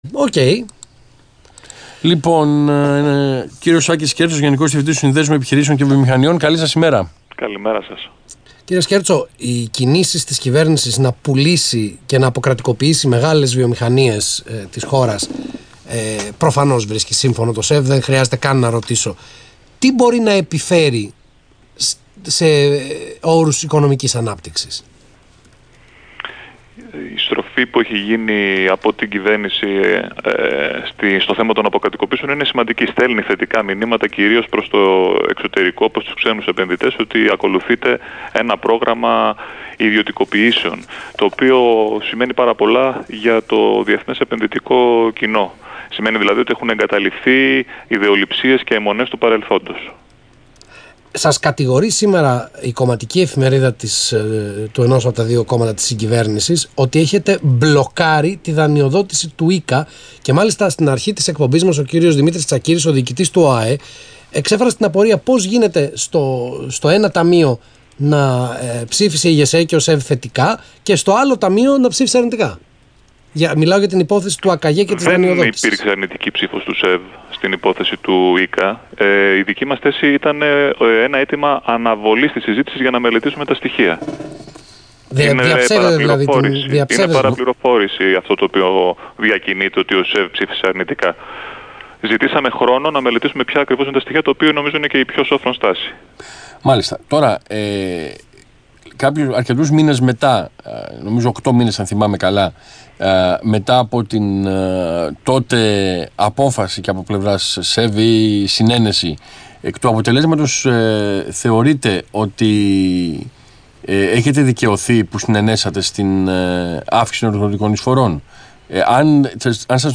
Συνέντευξη του Γενικού Διευθυντή του ΣΕΒ, κ. Άκη Σκέρτσου στον Ρ/Σ Αθήνα 9.84, 23/9/16